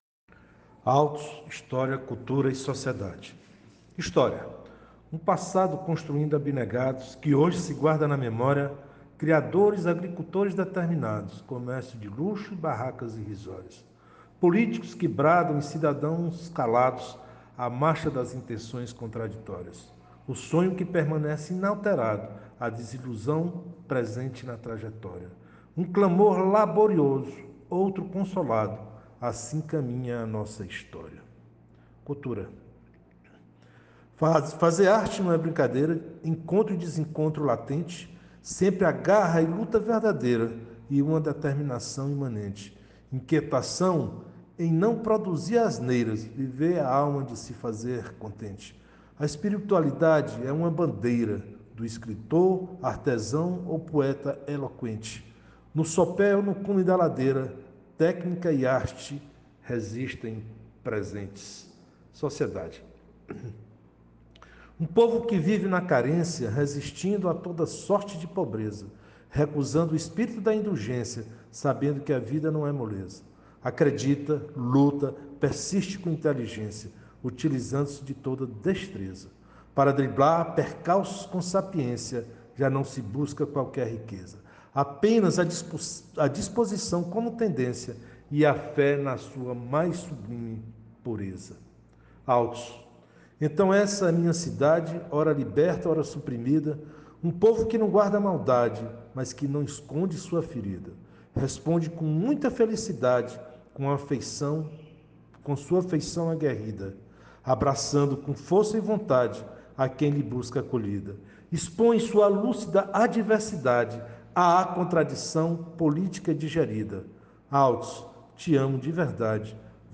02:05:00   Verso Recitado